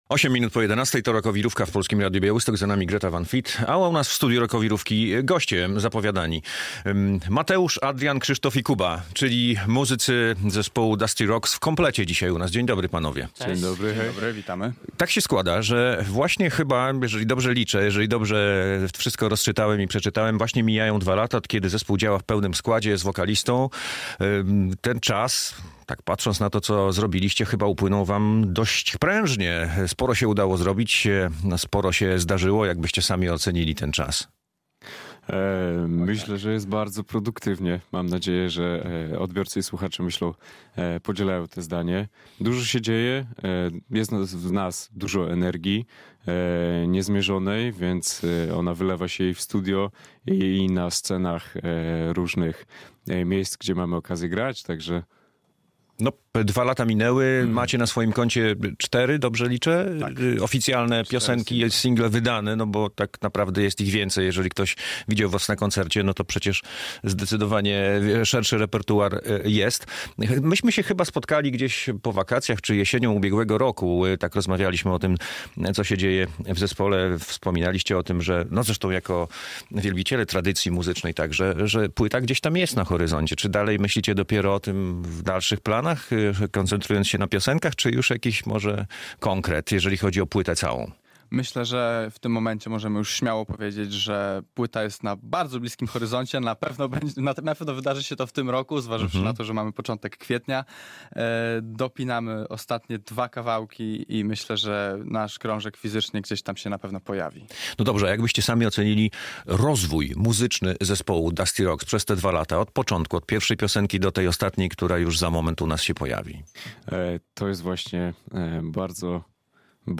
Radio Białystok | Gość | Dusty Rocks - białostocki zespół